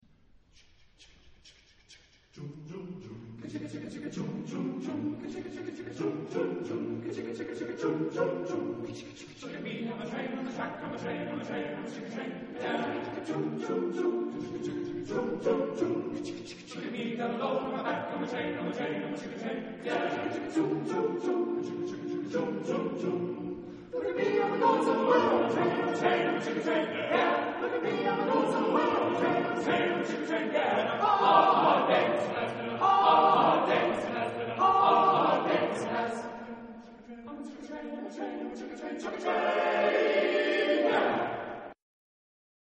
Texte en : anglais ; onomatopées
Genre-Style-Forme : Profane ; Swing ; Imitation
Caractère de la pièce : rapide ; swing
Tonalité : si bémol majeur